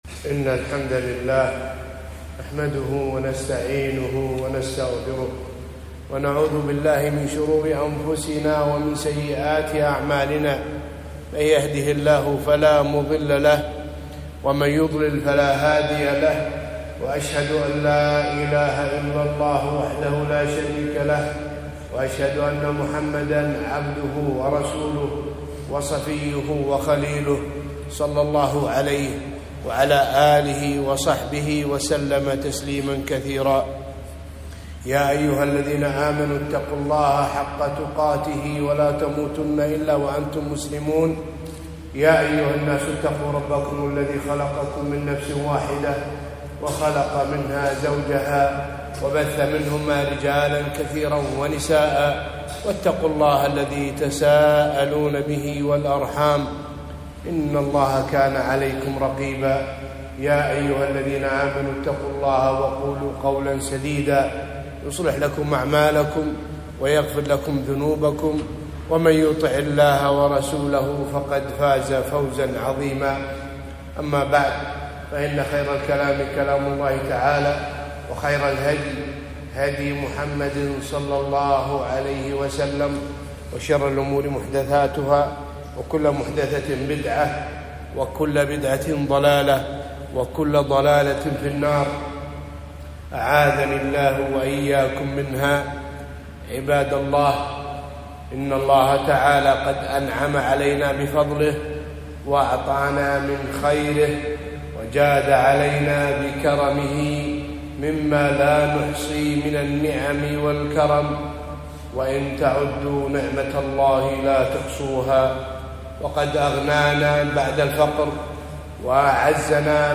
خطبة - شكر النعم